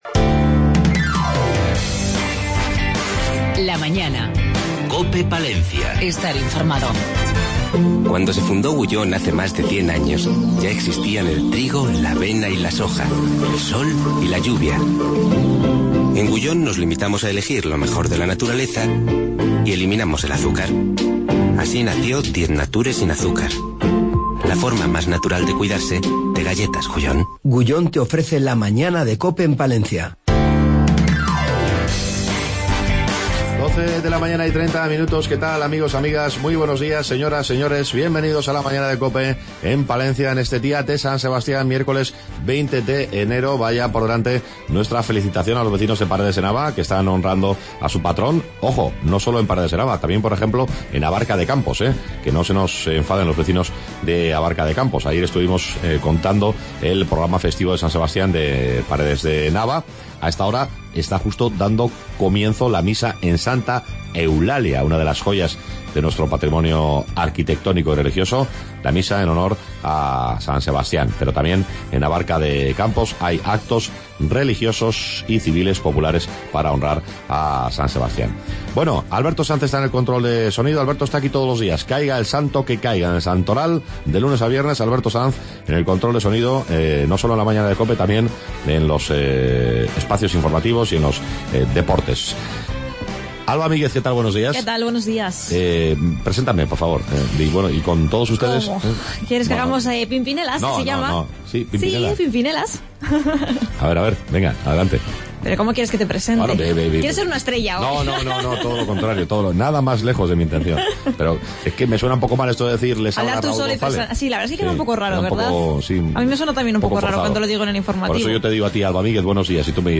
LA MAÑANA EN PALENCIA PRIMERA HORA ENTREVISTA CON MARTA DOMINGUEZ 20-01-16